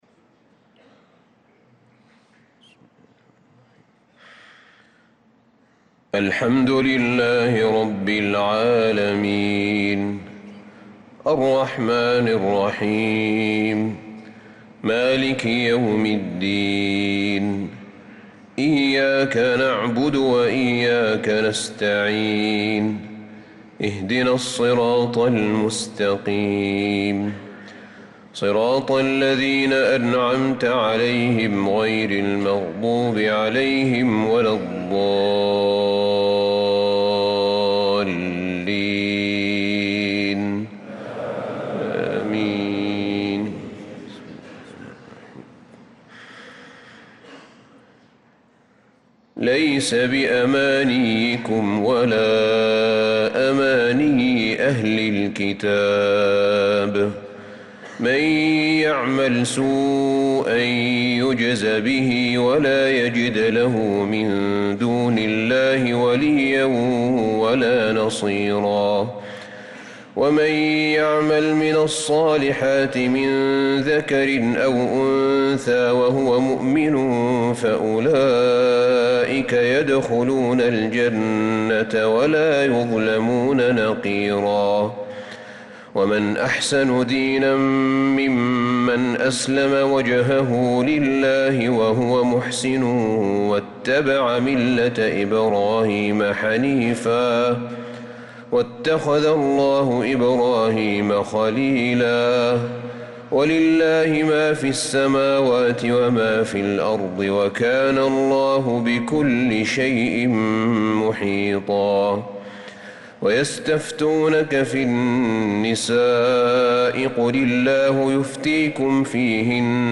صلاة الفجر للقارئ أحمد بن طالب حميد 20 ذو الحجة 1445 هـ
تِلَاوَات الْحَرَمَيْن .